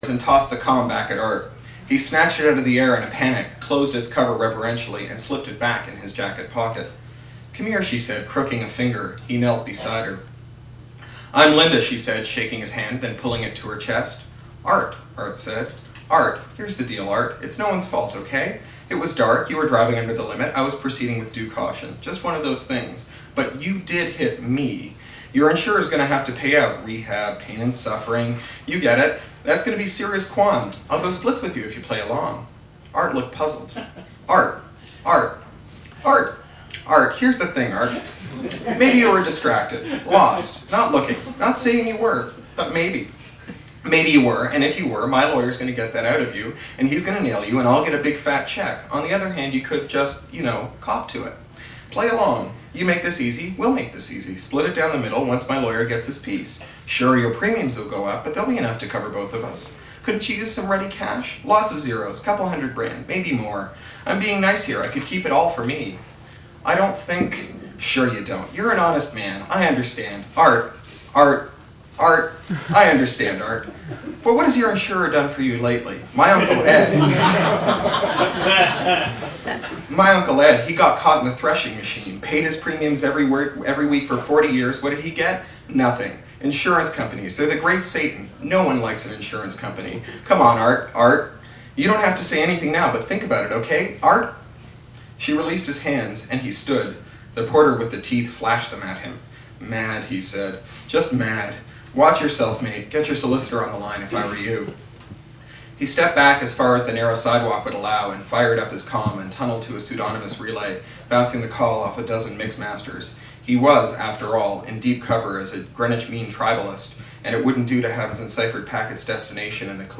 Here's footage from Cory Doctorow's reading at the Booksmith Wednesday night.
Here he is reading part of the fourth chapter from his new novel, Eastern Standard Tribe.